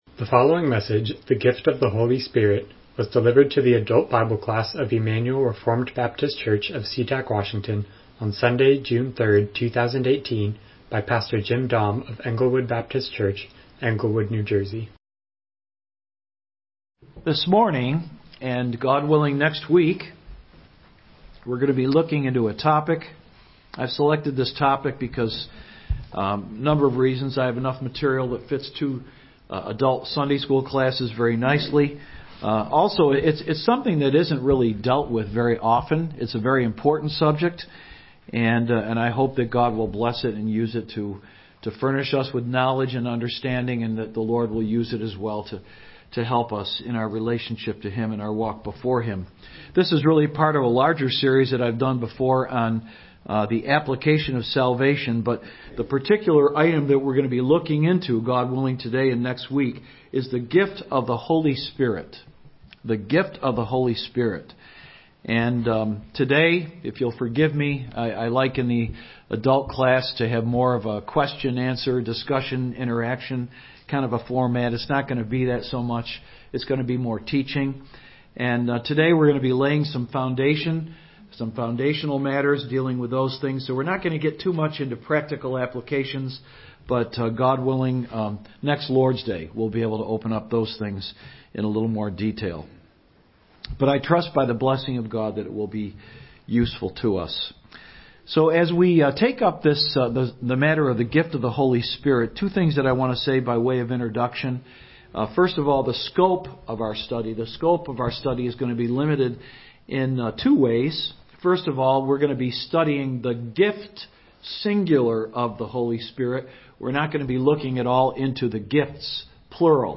Miscellaneous Service Type: Sunday School « A Survey of the Glory of God